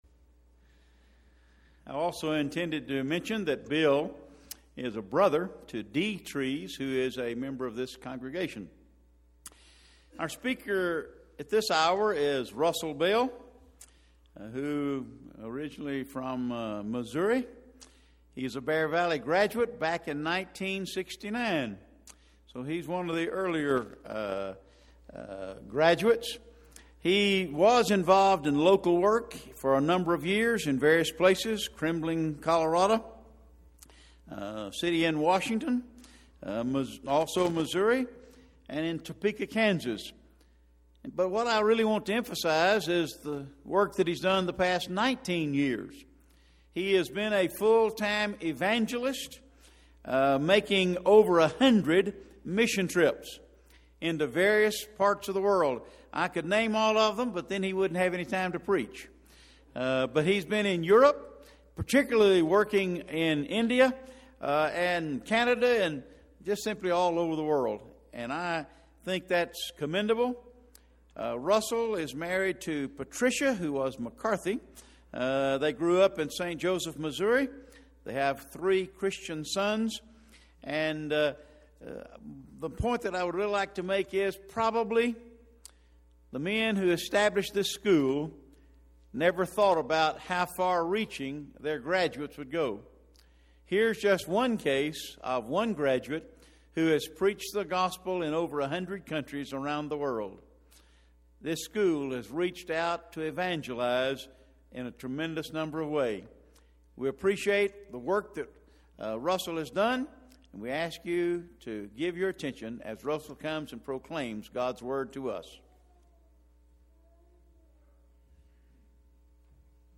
2011 Bear Valley Lectures - Who Is This Jesus? A Detailed Study of the Gospel of Mark